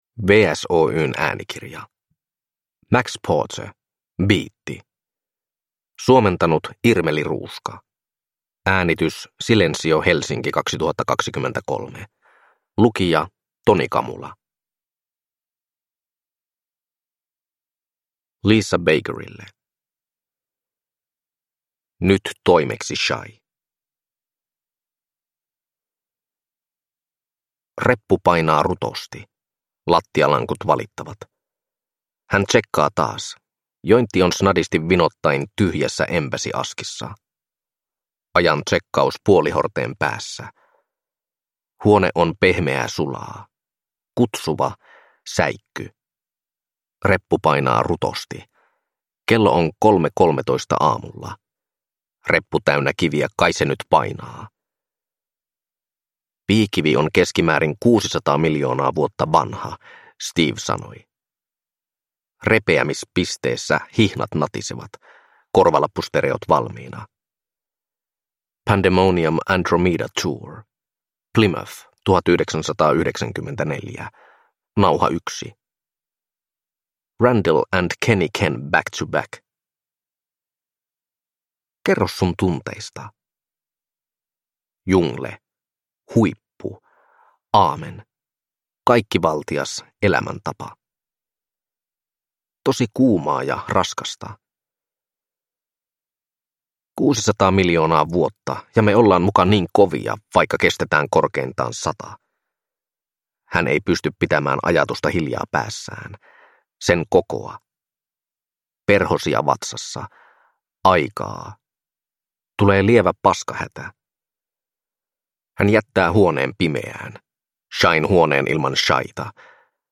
Biitti – Ljudbok – Laddas ner